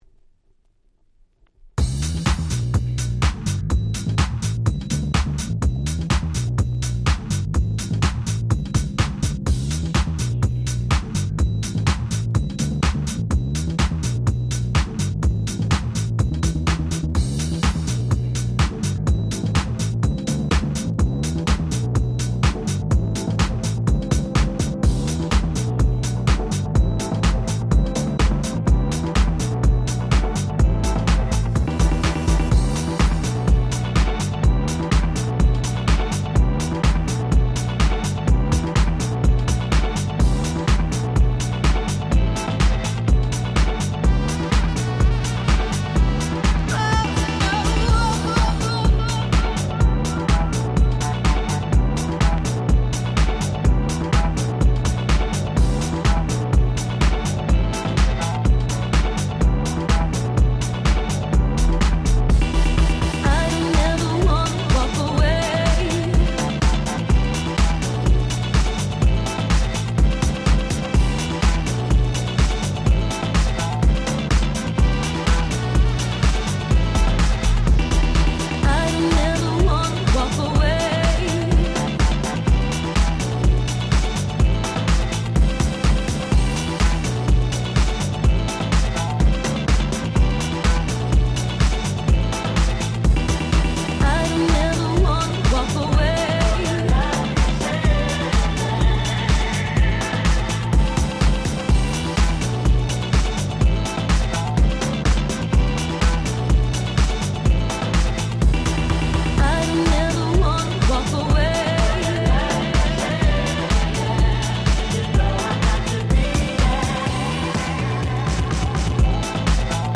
03' Nice Disco/Vocal House !!
内容はR&Bと言うよりはVocal House寄りのDiscoチューンではございますが非常に格好良いです！！